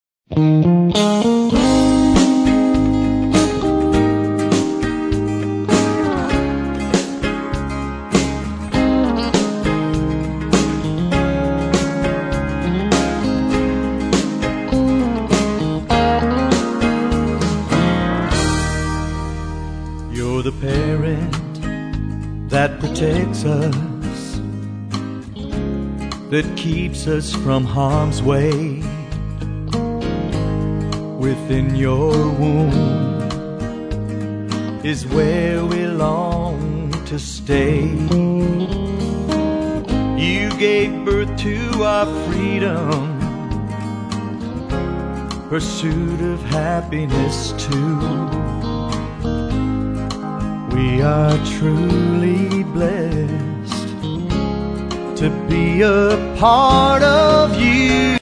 mp3 / Country